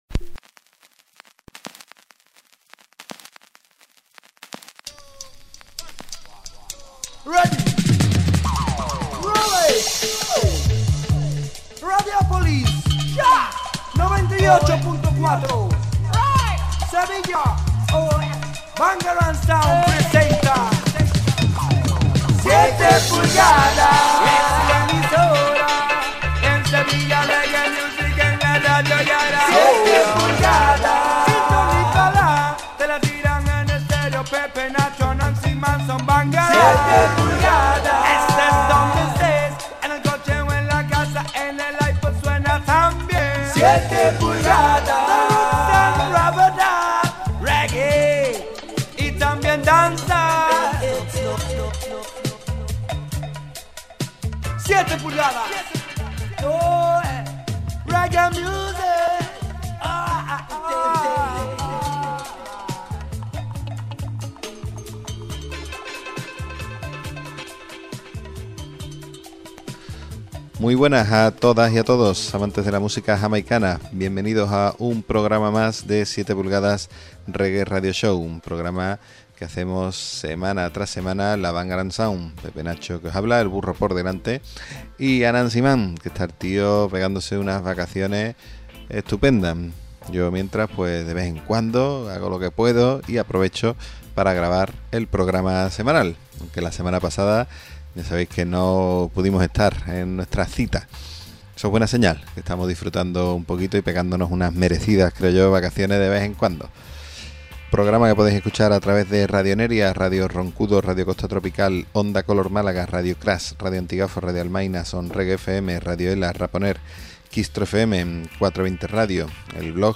os hará disfrutar del mejor ska, rocksteady y early reggae.
Mixtape